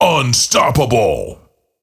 Фразы после убийства противника